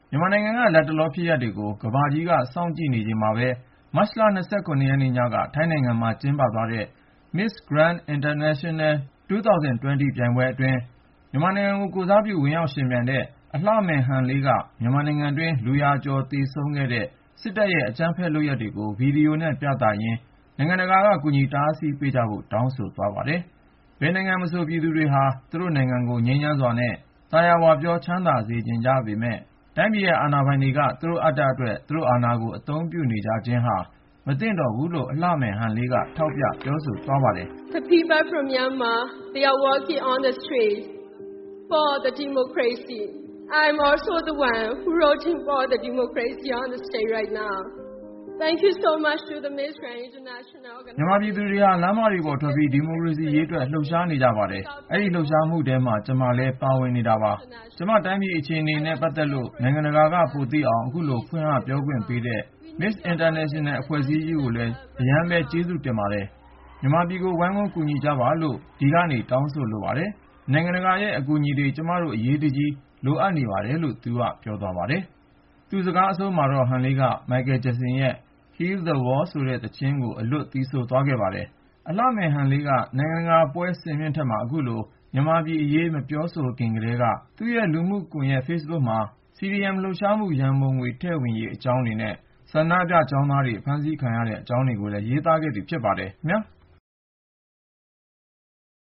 Heal the World ဆိုတဲ့ သီချင်းကို အလွတ် သီဆိုသွားခဲ့ပါတယ်။